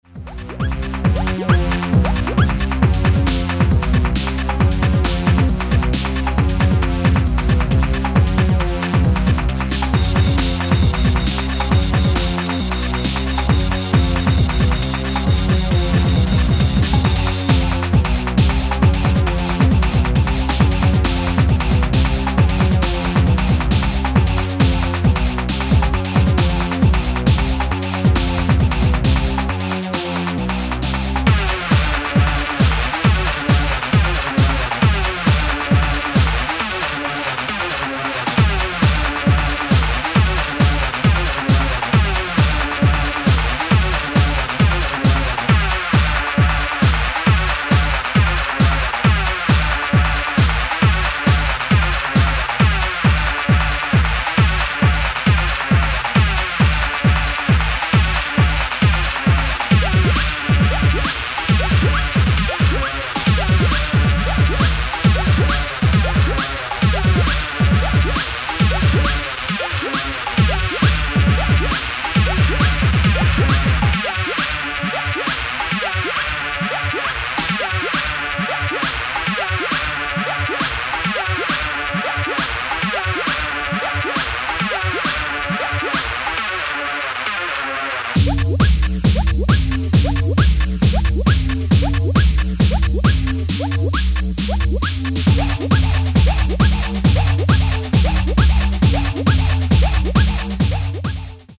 i like this a lot, really funky stuff there mate.